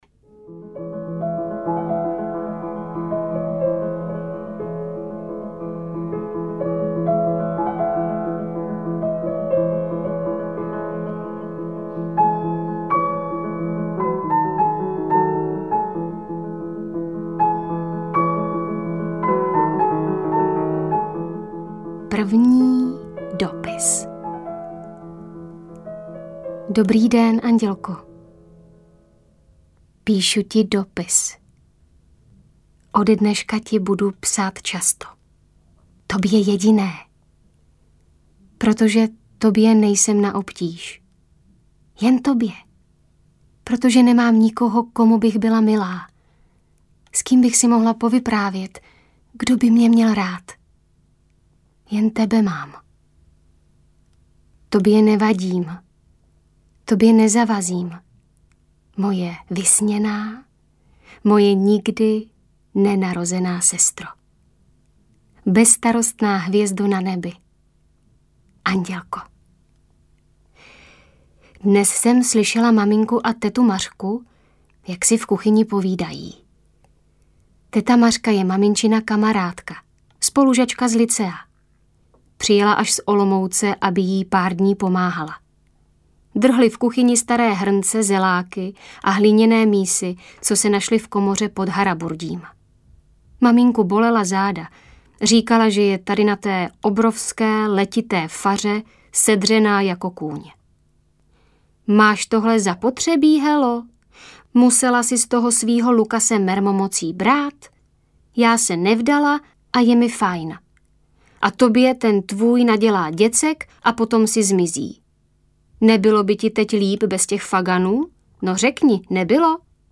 Přítel stesk audiokniha
Ukázka z knihy